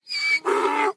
donkey